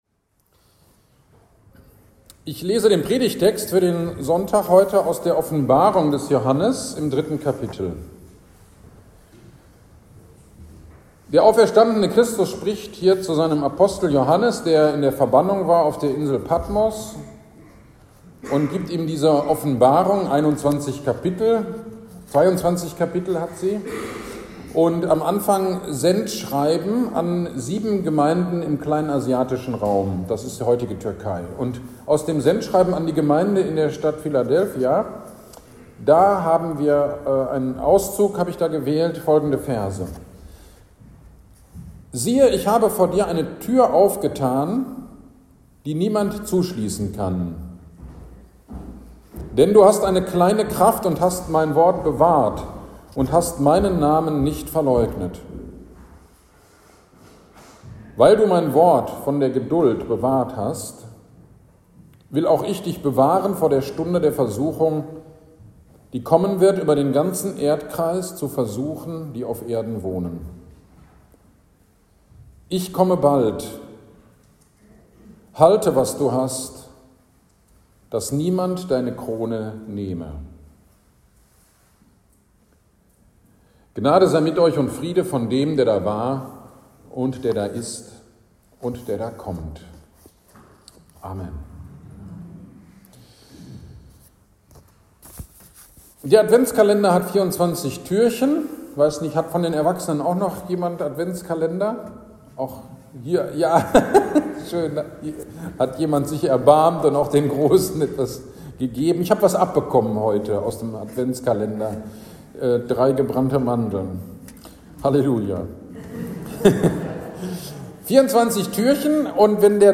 GD am 2. Advent 10.12.23 Predigt zu Offenbarung 3.8-11 - Kirchgemeinde Pölzig